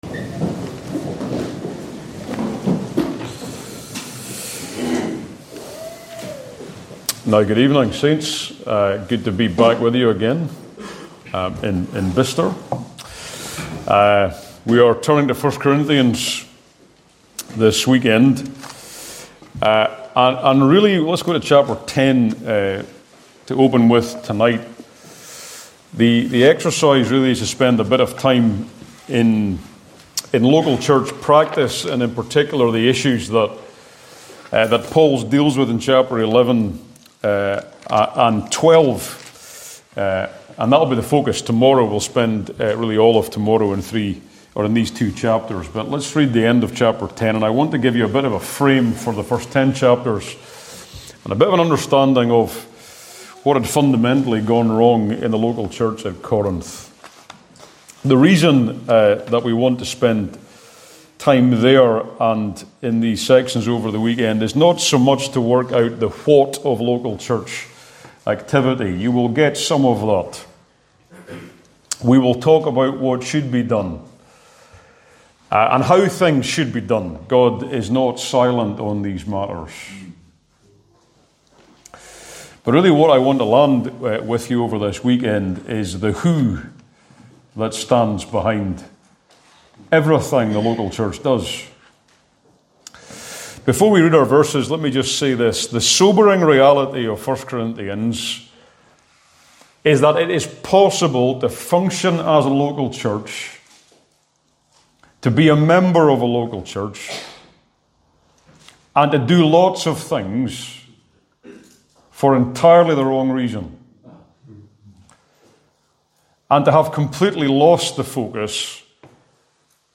In this challenging opening sermon of the series